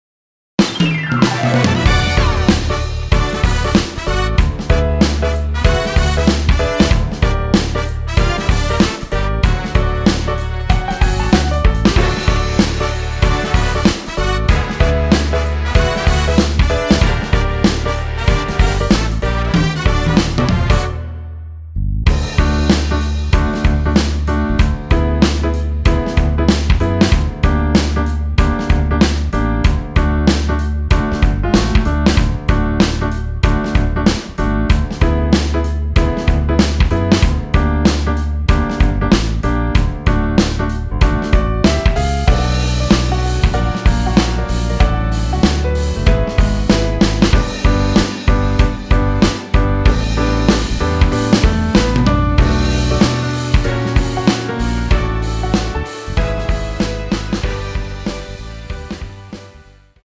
원곡보다 짧은 MR입니다.(아래 재생시간 확인)
원키(1절앞+후렴)으로 진행되는 MR입니다.
D
앞부분30초, 뒷부분30초씩 편집해서 올려 드리고 있습니다.
중간에 음이 끈어지고 다시 나오는 이유는